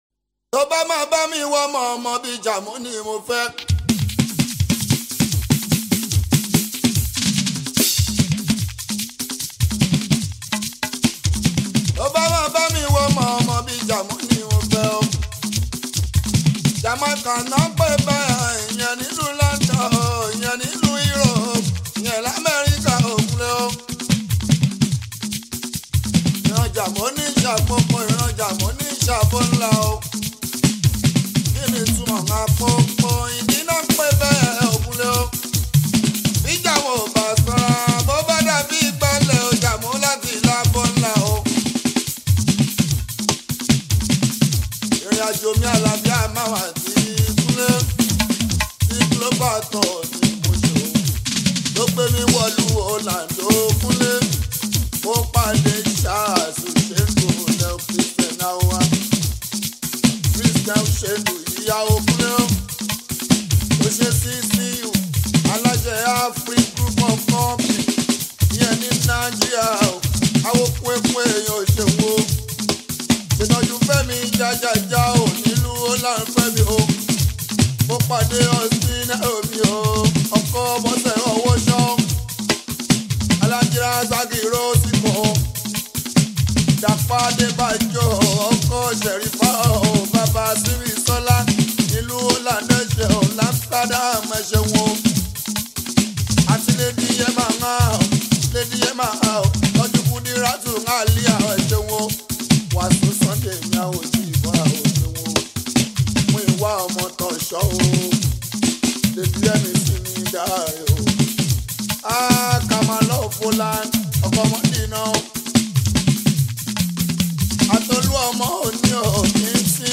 Nigerian Yoruba Fuji track
Yoruba Fuji Sounds
be ready to dance to the beats